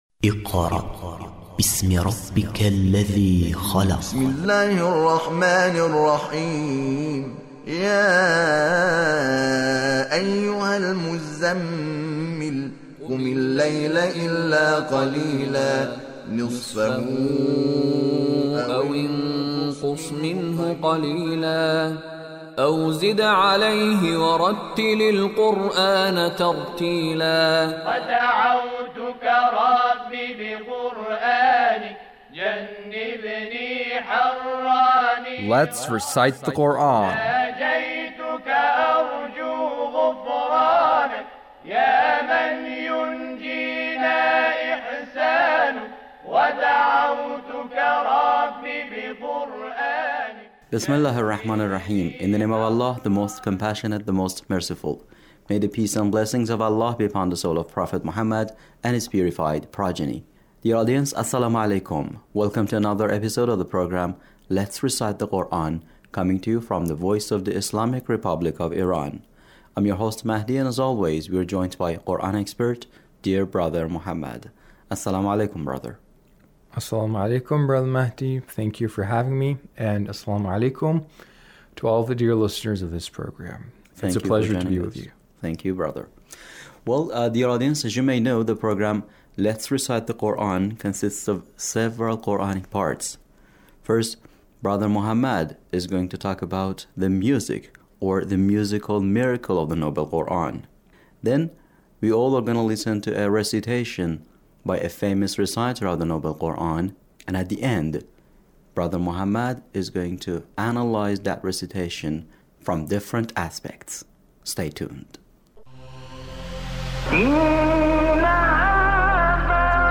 Recitation of Sheikh Shahat Anwar